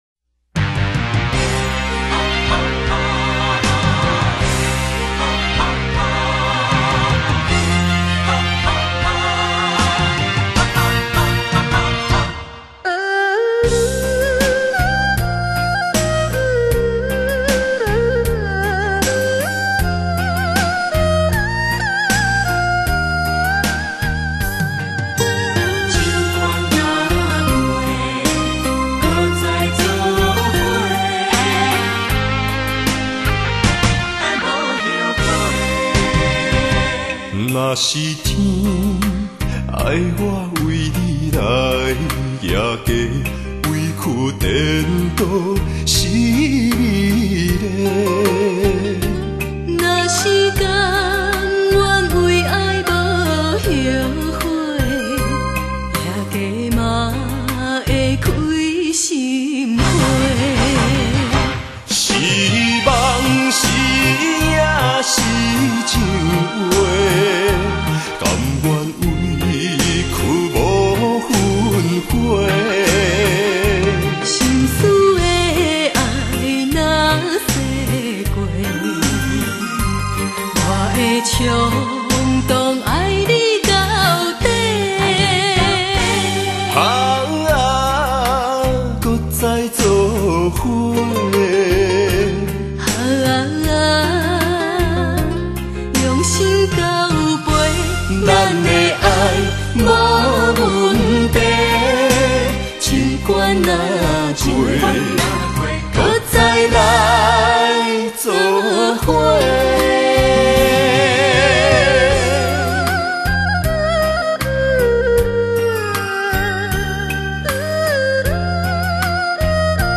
音樂類型：華語台語
台湾闽南语电视剧主题歌的合辑。